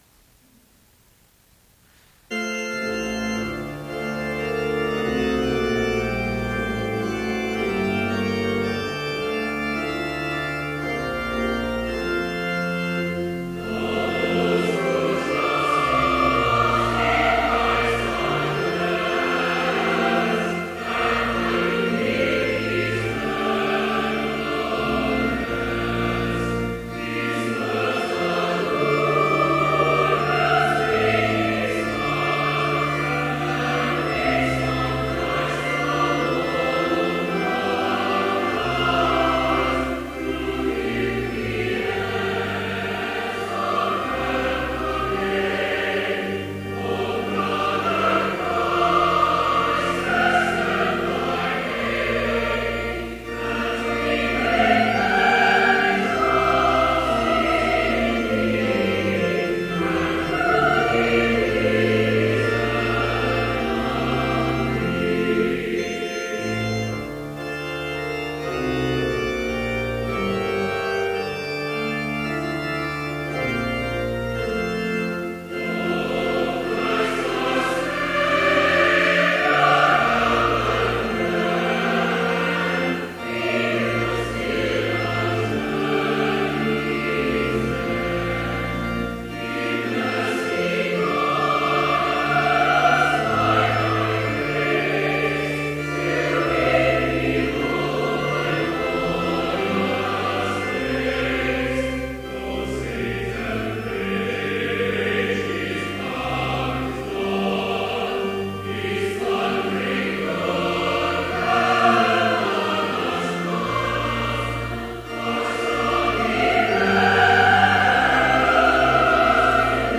Vespers service in Bethany Lutheran College's chapel, April 30, 2014, (audio available) with None Specified preaching.
Complete service audio for Evening Vespers - April 30, 2014
Versicles and Gloria Patri (led by the choir)